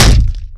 boat_heavy_2.wav